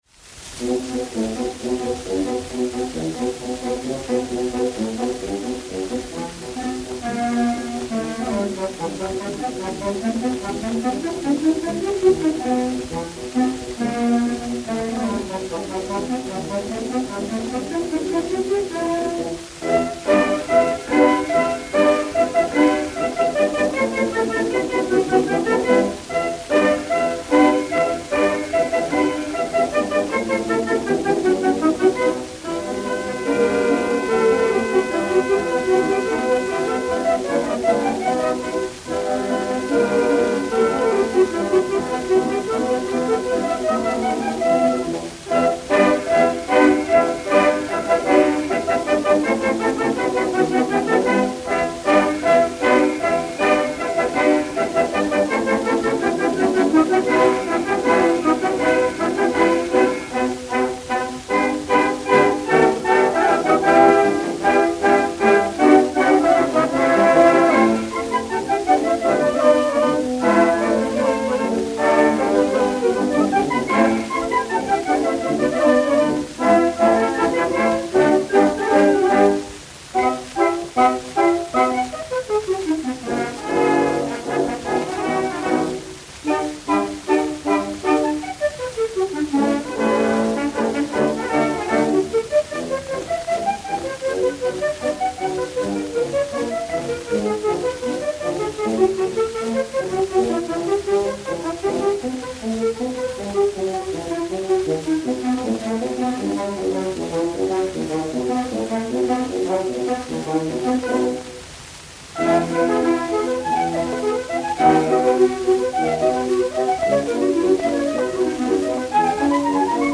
Suite d'orchestre des Deux Pigeons
Musique de la Garde Républicaine